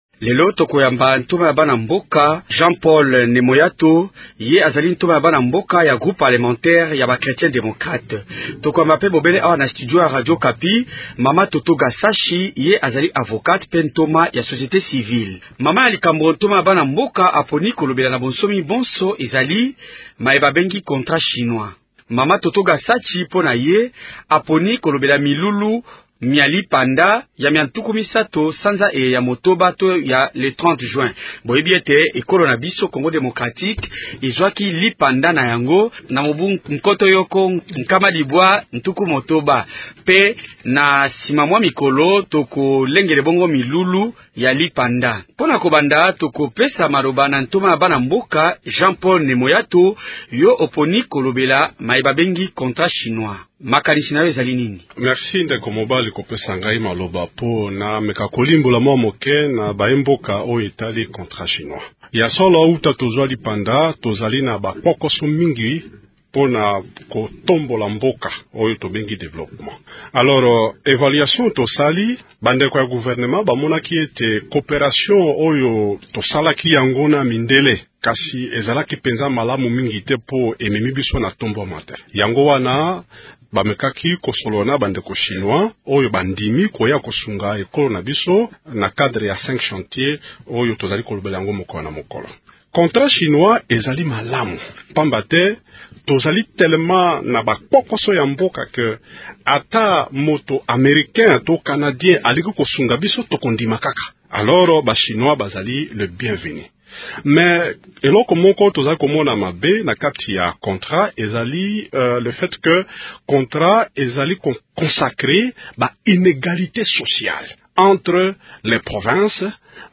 Ce sont là les deux thèmes du débat en lingala de ce soir.